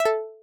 stream-off.ogg